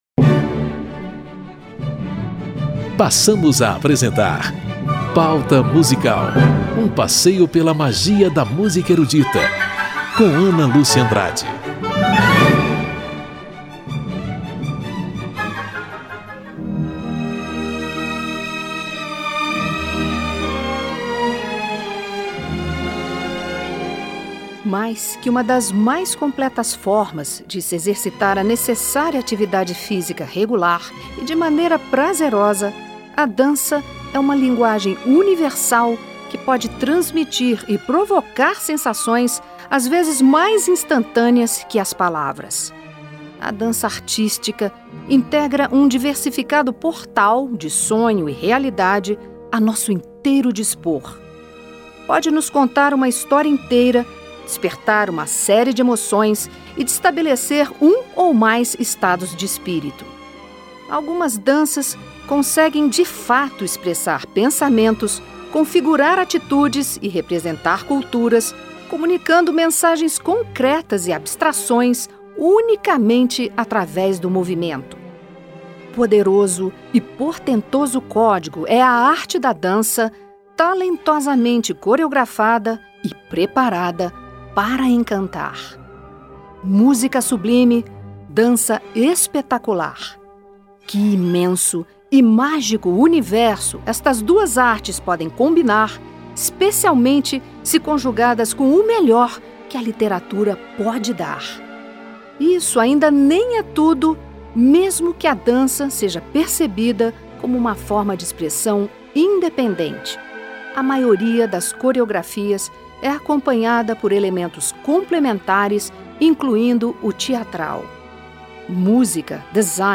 Música para bailado e comédia francesa em obras de Léo Delibes, inspiradas em temas da mitologia grega, literatura fantástica de E.T.A. Hoffmann e peça teatral de Victor Hugo.